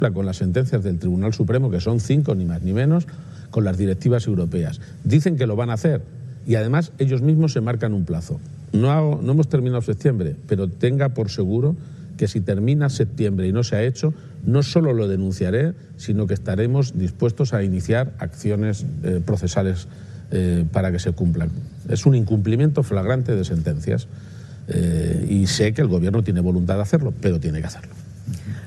Presidente Martes, 23 Septiembre 2025 - 11:15am El jefe del Ejecutivo castellanomanchego ha asegurado hoy, en una entrevista realizada en CMMedia, que si el Gobierno de España no cumple las sentencias que debe asumir respecto al trasvase, está dispuesto a llevar el asunto a los tribunales. garcia-page_sentencias_aguamp3.mp3 Descargar: Descargar